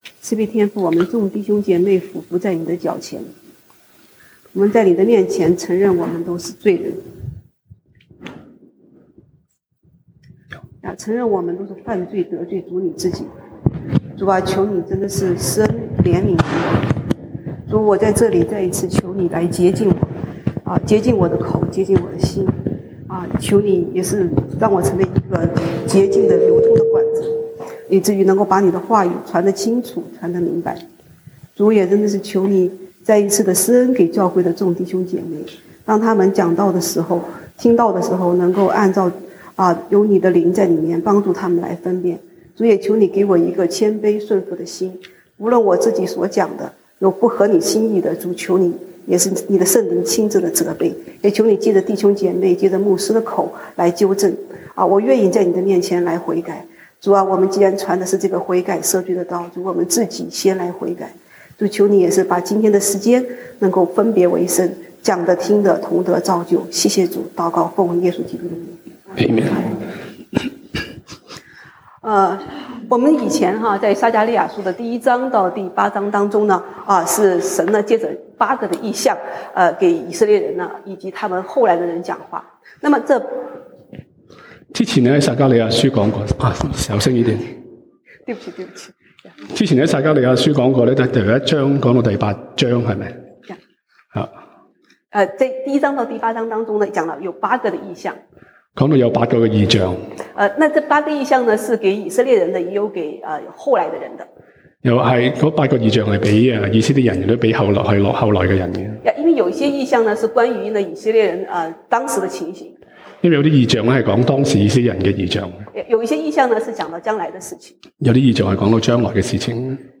西堂證道(粵語/國語) Sunday Service Chinese: 撒迦利亞 Zechariah 10:1-2